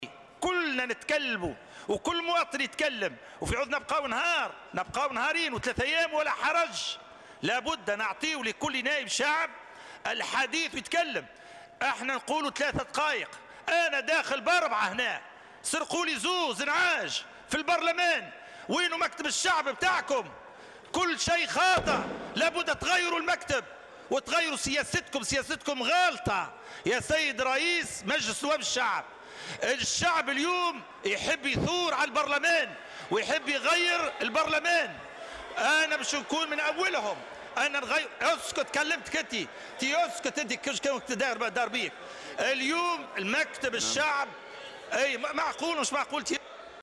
تلاسن في البرلمان ومناوشات بسبب نعت النّواب بـ "النّعاج"